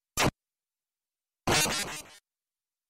game_over.wav